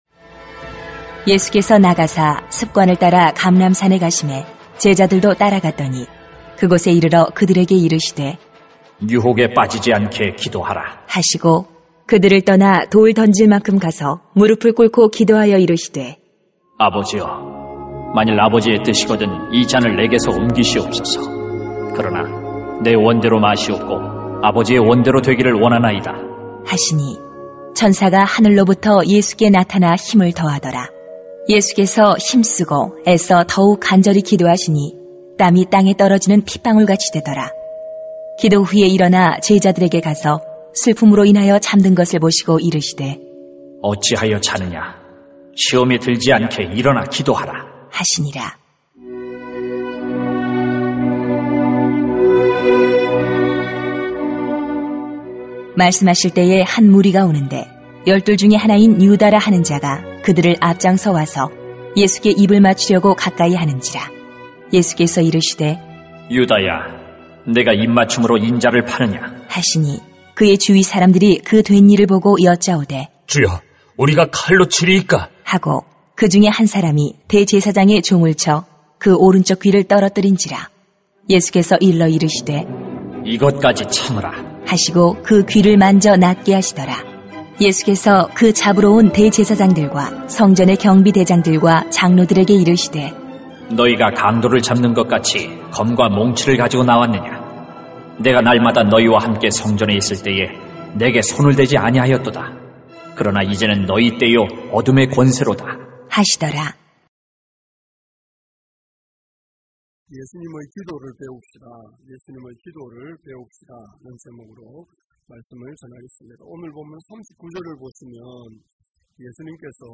[눅 22:39-53] 예수님의 기도를 배웁시다 > 새벽기도회 | 전주제자교회